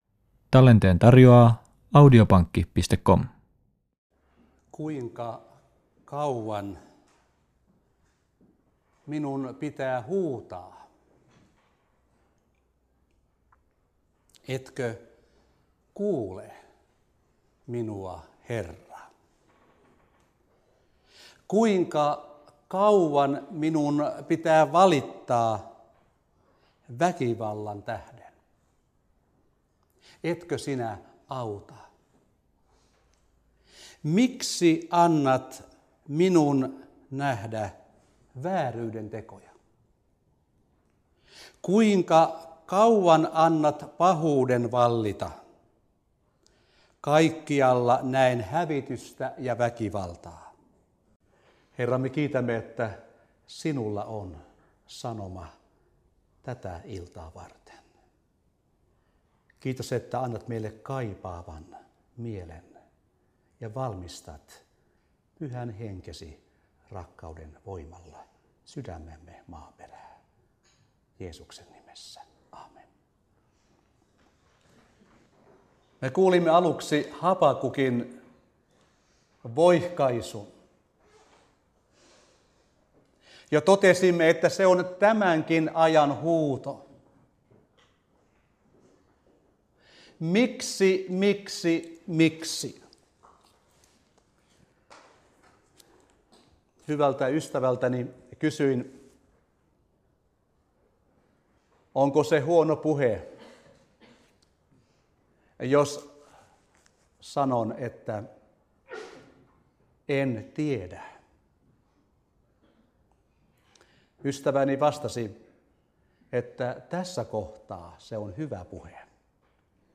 Tämä puhesarja pyrkii raottamaamn Raamatun avulla elämäämme kuuluvien asioiden ja kysymysten verhoa.
Lohjalla 2.11.2006 https